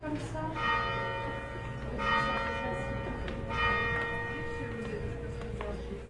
法国实地记录 3 " fr9 07 bells f
描述：法国教堂钟声的简短录音。
标签： 气氛 现场记录 法国 氛围
声道立体声